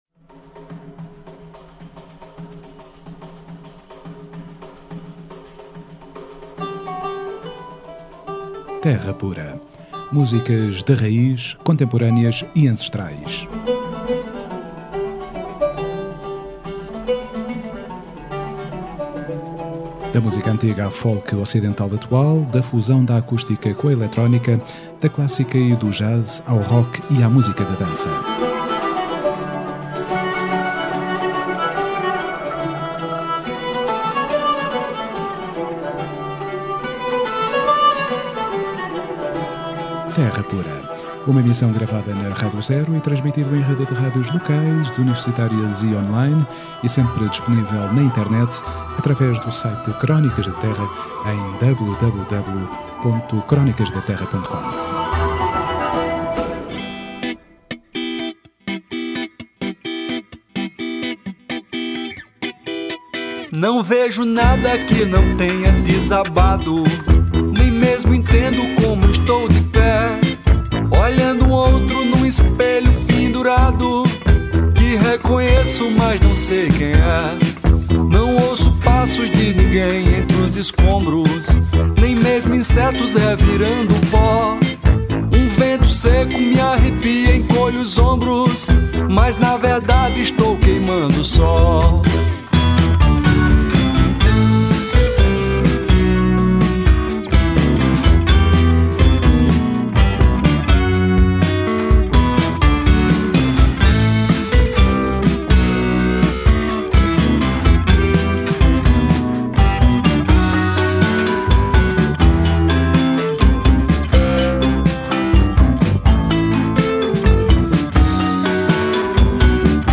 Terra Pura 07ABR14: Entrevista Siba